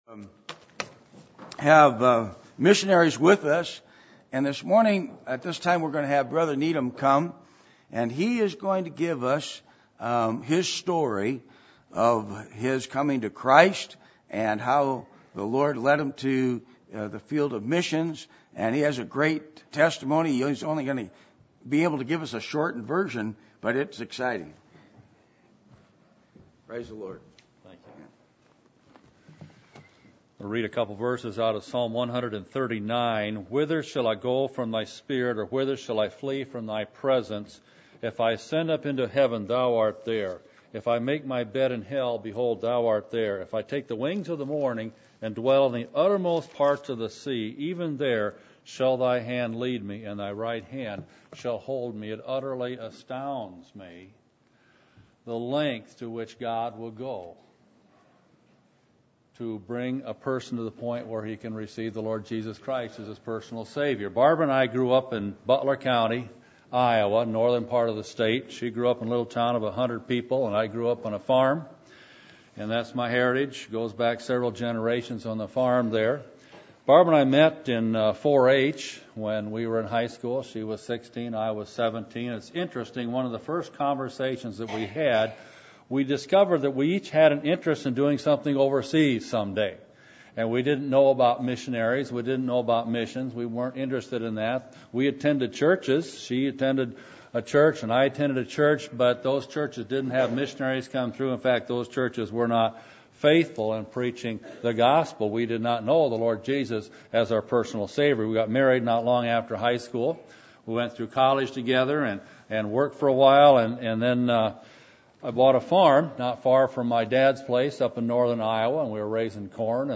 Missions Conference 2014 Passage: 2 Corinthians 5:1-21 Service Type: Sunday Morning %todo_render% « Whose Will Are You Seeking After?